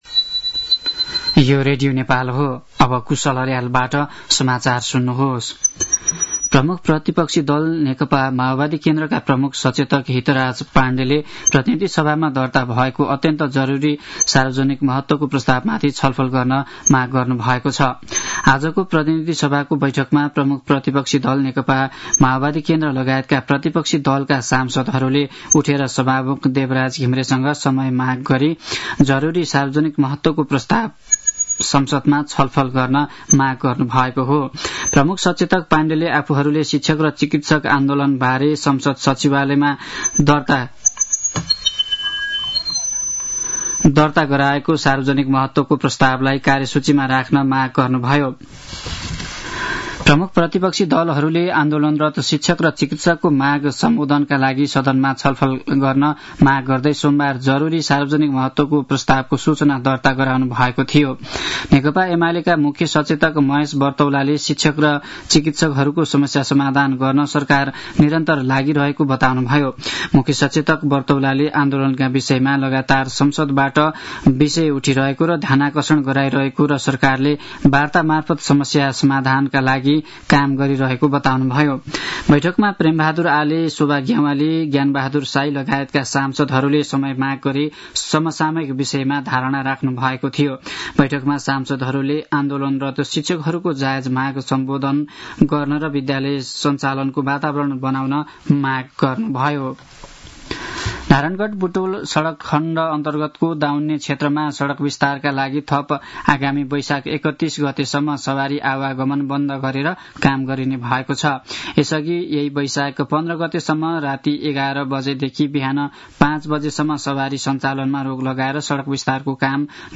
मध्यान्ह १२ बजेको नेपाली समाचार : १६ वैशाख , २०८२